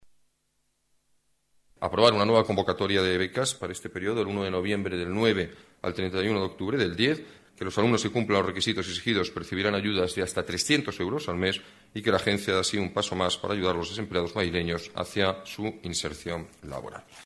Nueva ventana:Declaraciones del alcalde sobre becas para desempleados